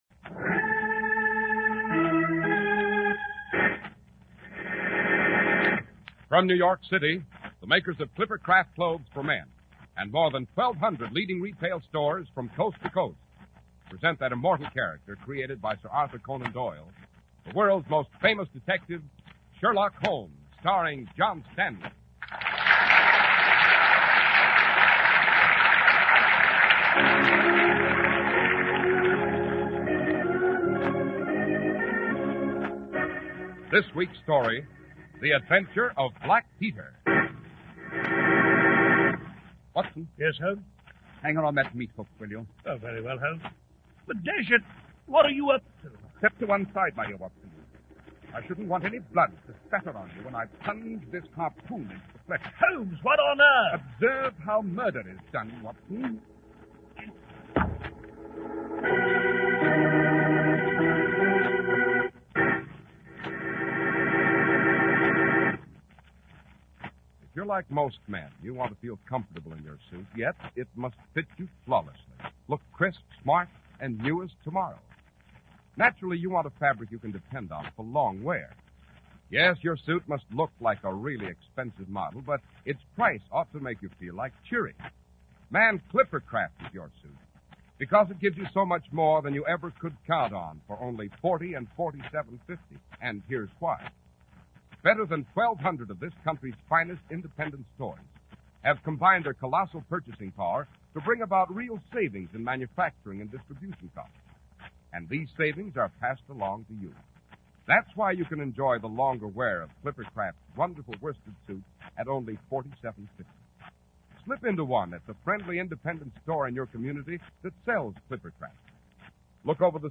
Radio Show Drama with Sherlock Holmes - Black Peter 1948